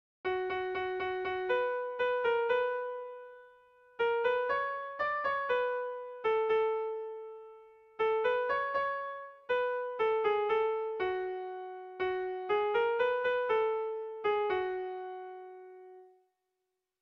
Sehaskakoa
ABD